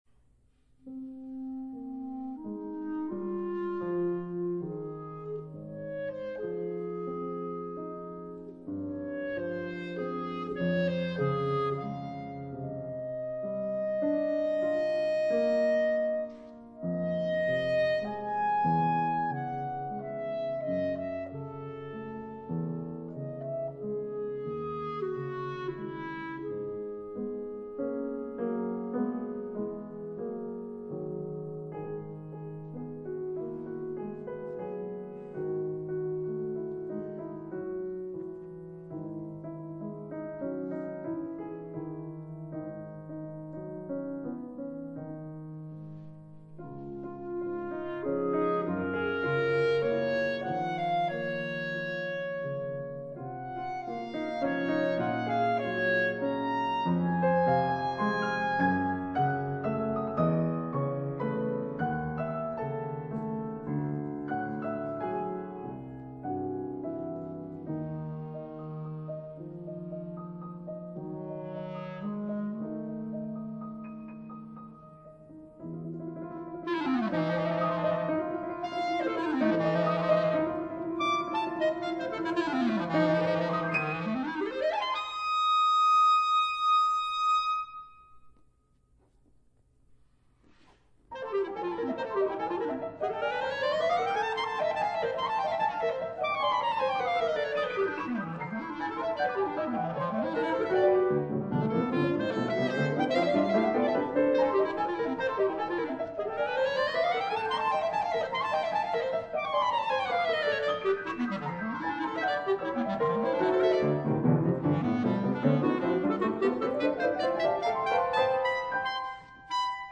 clarinet
piano